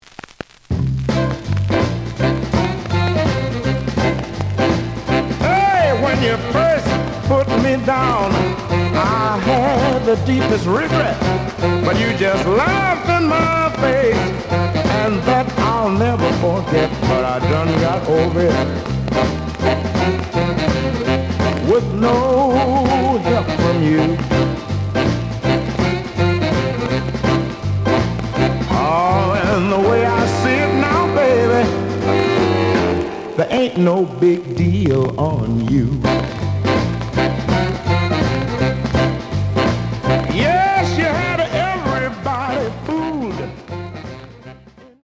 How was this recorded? The audio sample is recorded from the actual item. Some noise on B side due to noticeable scratches.)